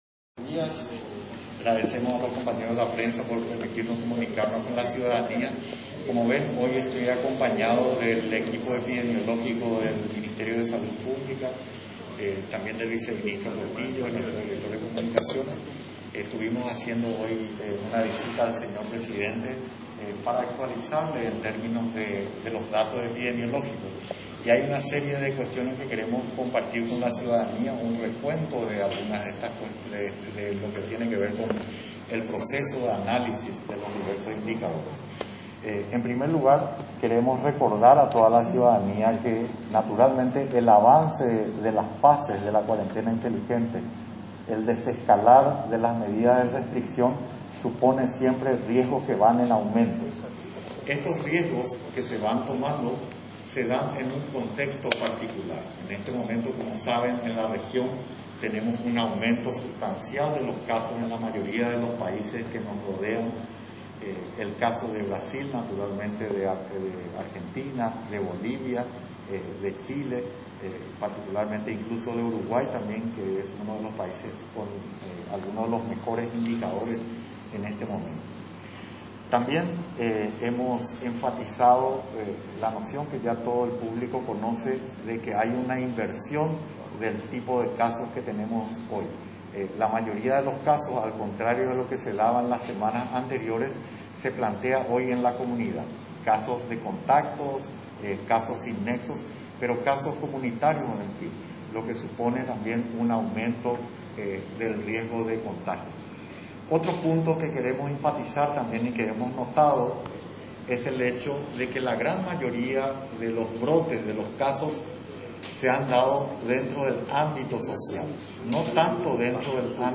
21-CONFERENCIA-RESUMIDA-MAZZOLENI.mp3